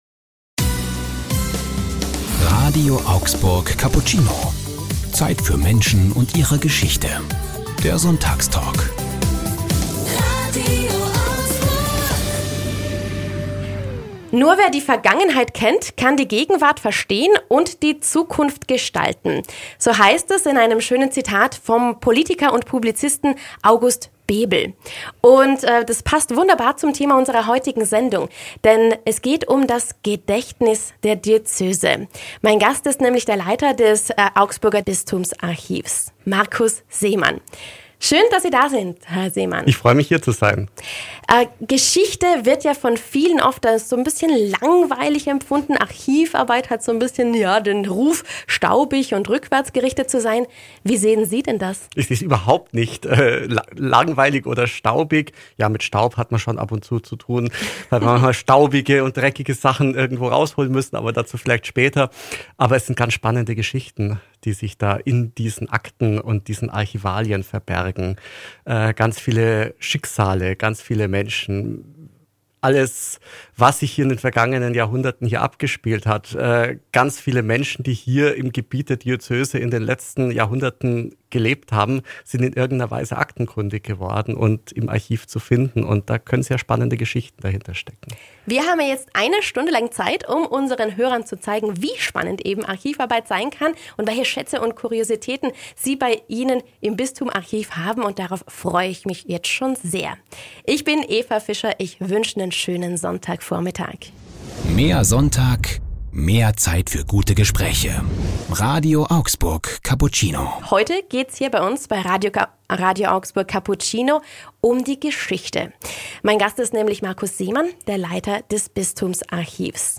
Sonntagstalk ~ RADIO AUGSBURG Cappuccino Podcast